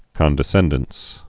(kŏndĭ-sĕndəns)